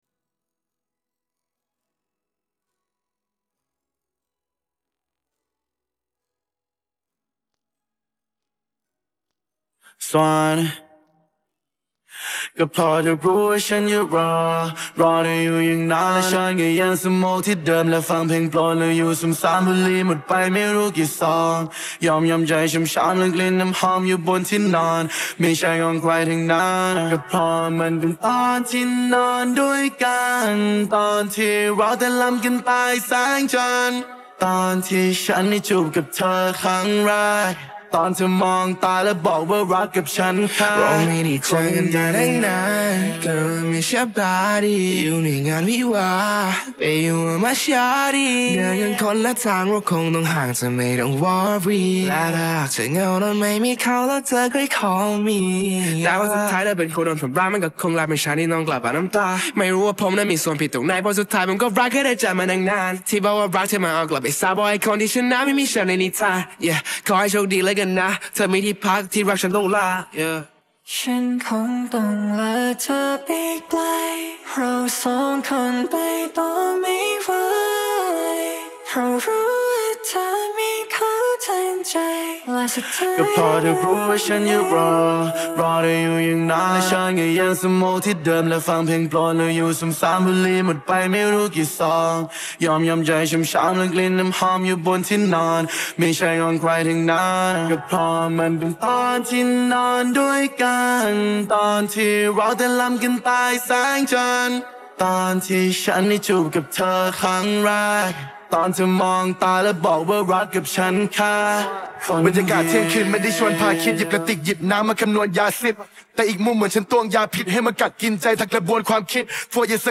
ส่วนเสียง